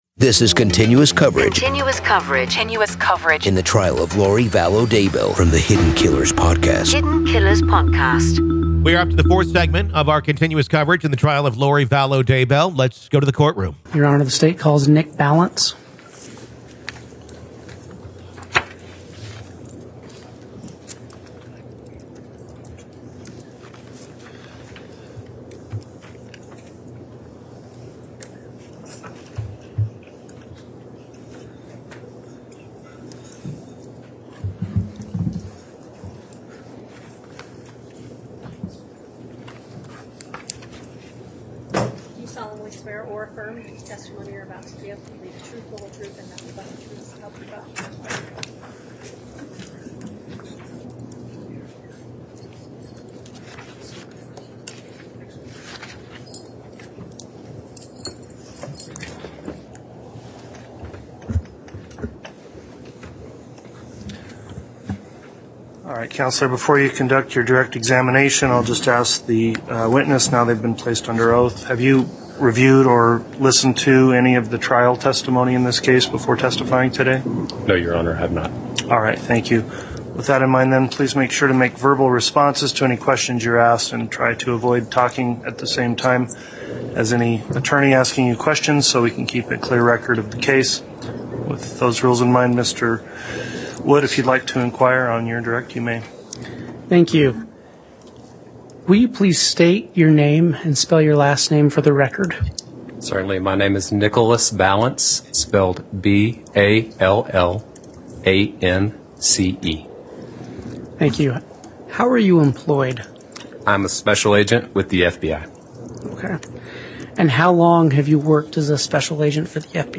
The Trial Of Lori Vallow Daybell Day 9 Part 4 | Raw Courtroom Audio